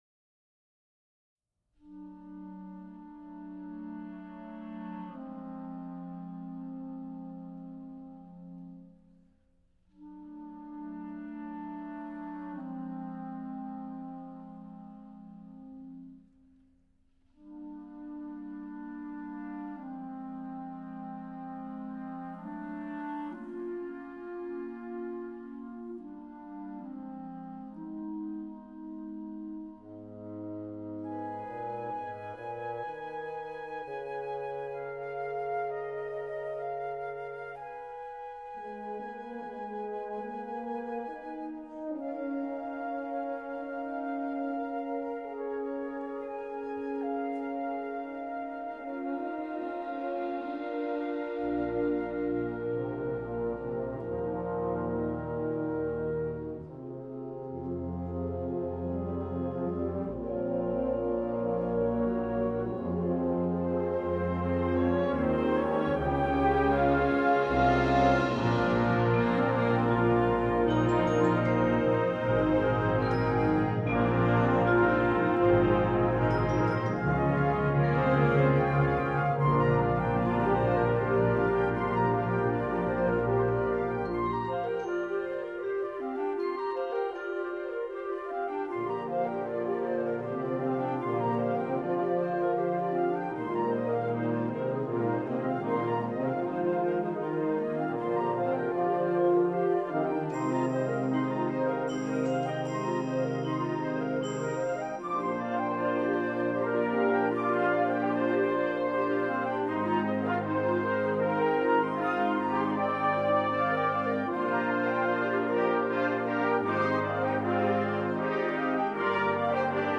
Genre: Band
Flute 1/2
Oboe
Bassoon
Bass Clarinet
Alto Saxophone 1/2
Trumpet 1
Horn 1/2
Trombone 1/2
Tuba
Timpani (3 drums)
Percussion 3/4 (suspended cymbal, triangle, snare drum)
Percussion 5/6 (suspended cymbal, crash cymbals, bass drum)